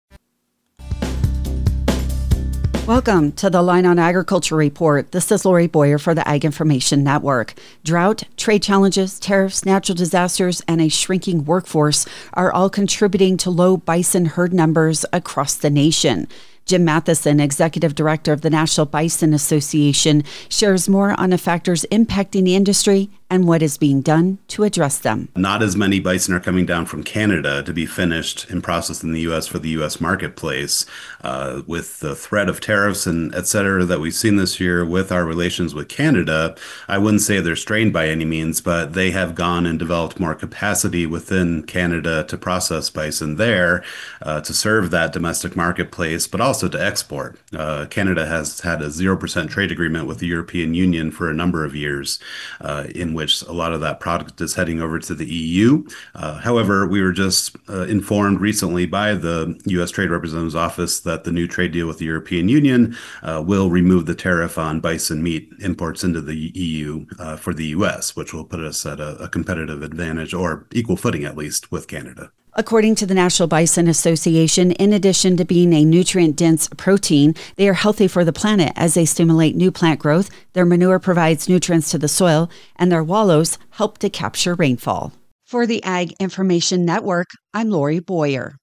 Reporter